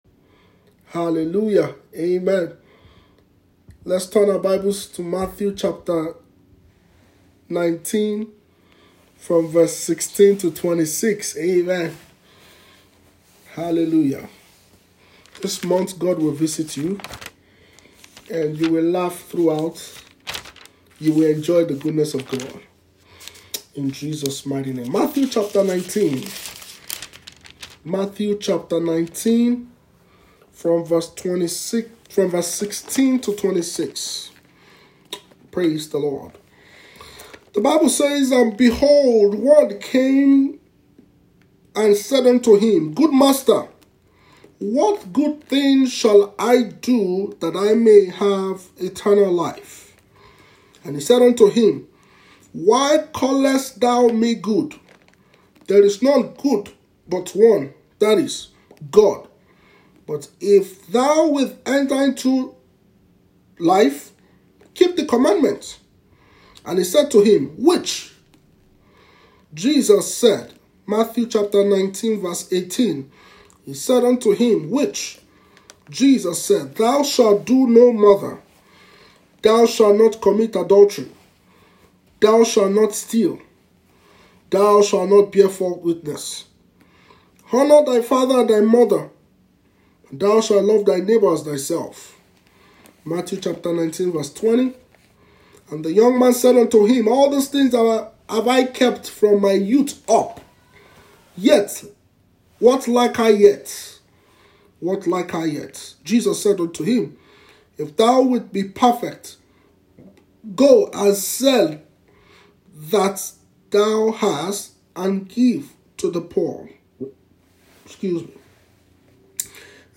All things are possible when we believe CATEGORY: SERMON